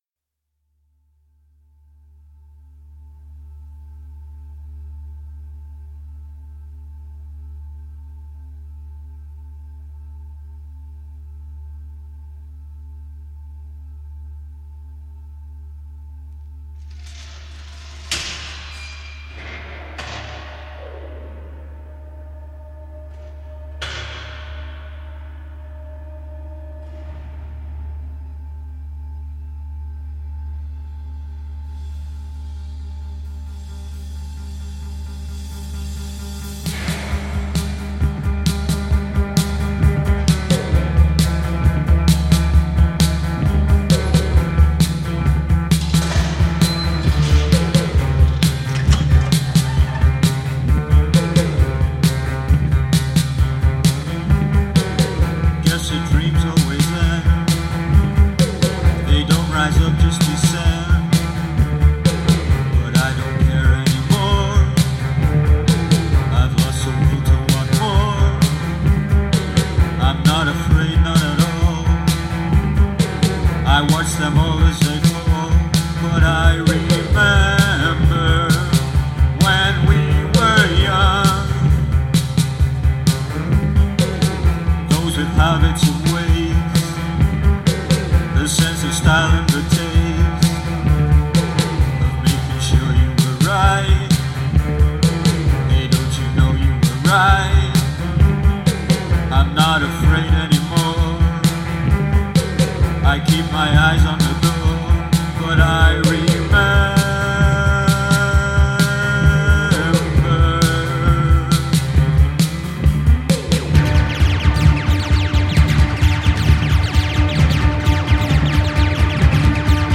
آهنگ پست-پانک آهنگ راک